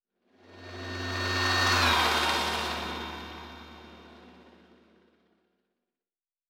pgs/Assets/Audio/Sci-Fi Sounds/Movement/Fly By 02_8.wav at master
Fly By 02_8.wav